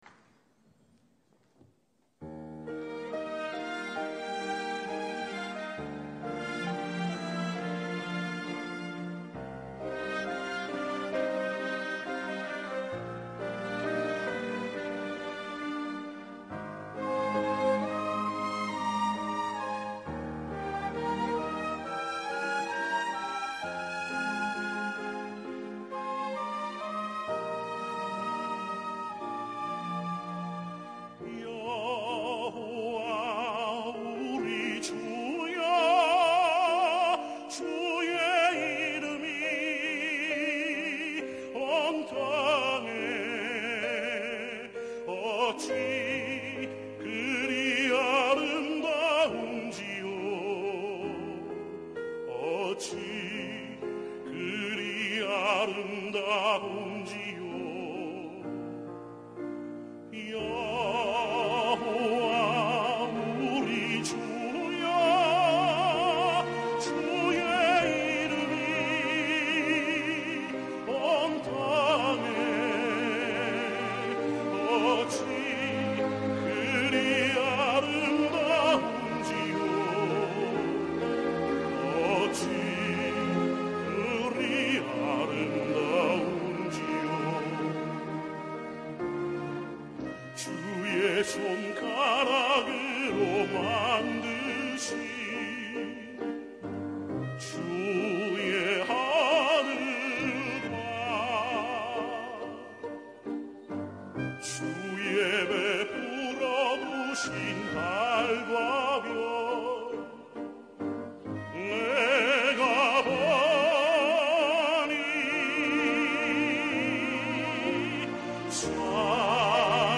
찬양 여호와 우리 주여